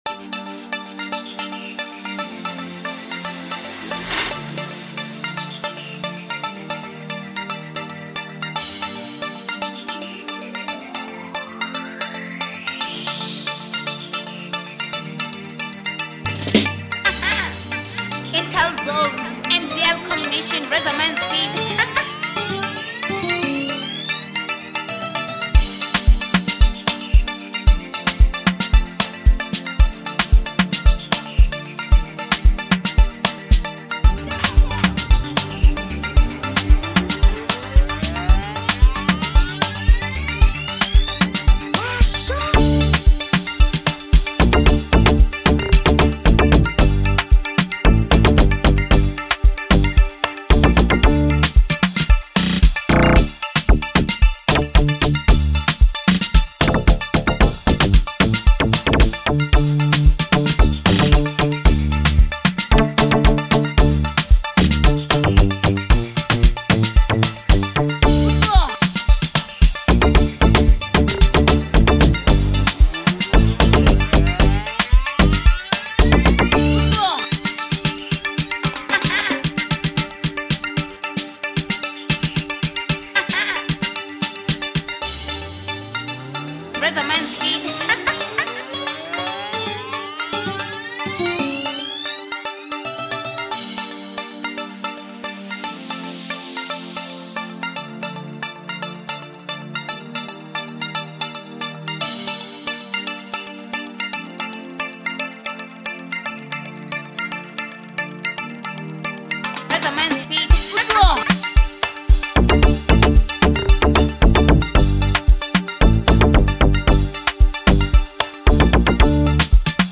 04:44 Genre : Amapiano Size